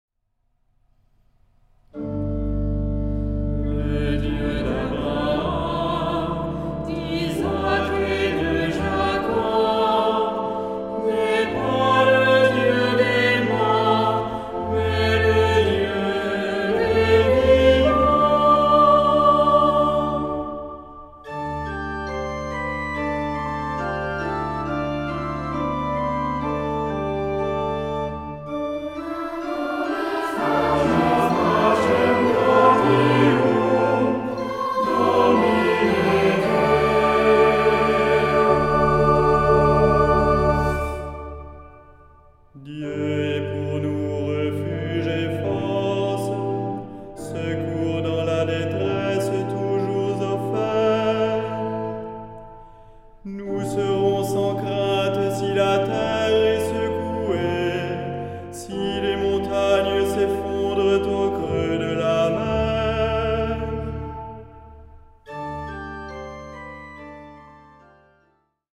Genre-Stil-Form: Tropar ; Psalmodie
Charakter des Stückes: andächtig
Chorgattung: SATB  (4 gemischter Chor Stimmen )
Instrumente: Orgel (1)
Tonart(en): d-moll